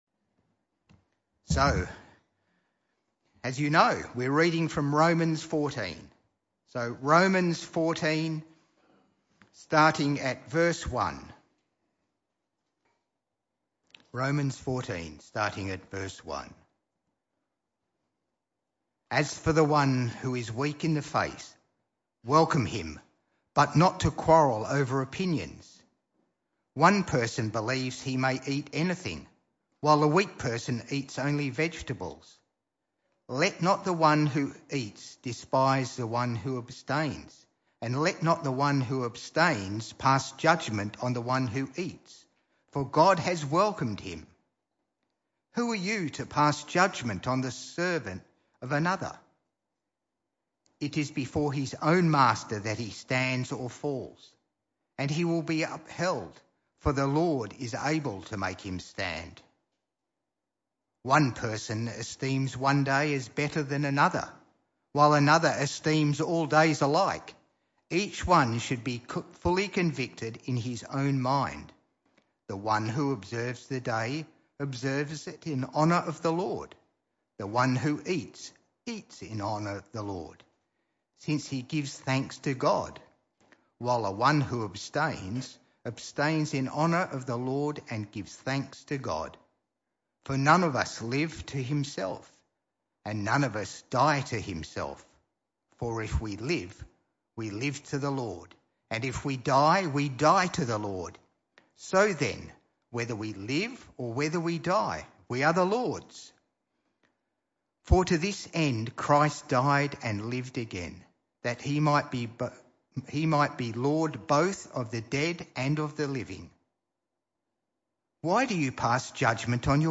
This talk was a one-off talk in the PM Service.
Romans 14:1-23 Service Type: Evening Service This talk was a one-off talk in the PM Service.